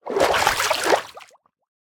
assets / minecraft / sounds / liquid / swim10.ogg
swim10.ogg